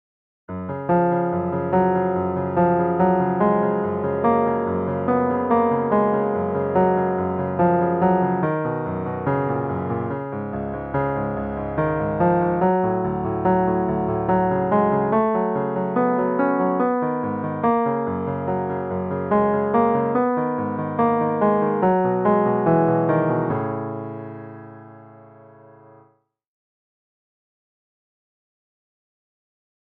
The following audio clip is an instrumental elaboration on a section of the song my siblings and I did for my parents.